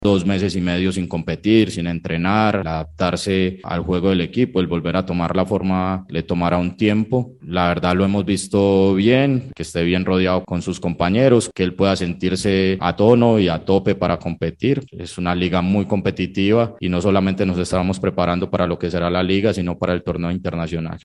Alejandro Restrepo - DT Nacional